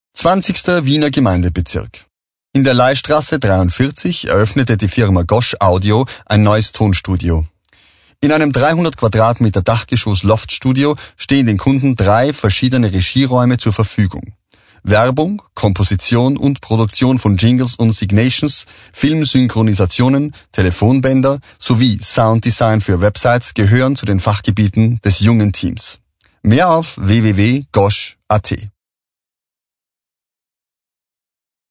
SPRECHER DEMO Hochdeutsch - WERBETEXT GOSH AUDIO 2.mp3